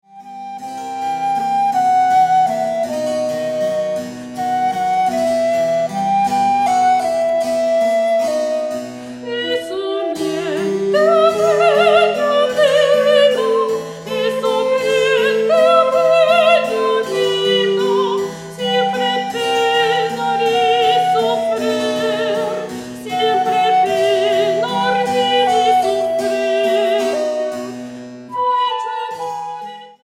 Música barroca portuguesa y su influencia italo-española
Modinha a 3
Tepee Studio-Brussels